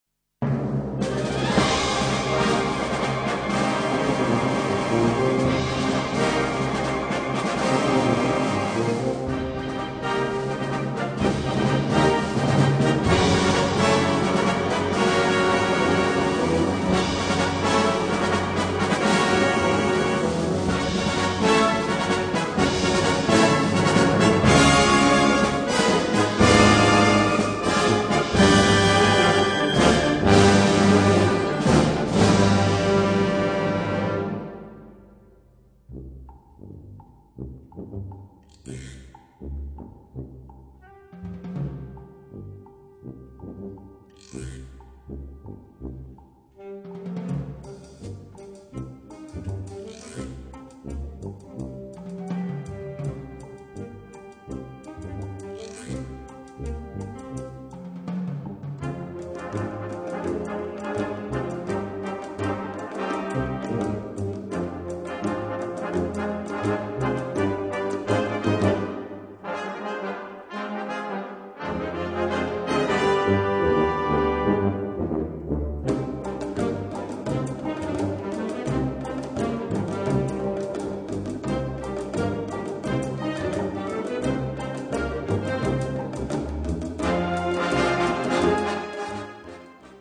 Kategorie Blasorchester/HaFaBra
Unterkategorie Musik aus Südamerika (...im Stile)
Besetzung Ha (Blasorchester)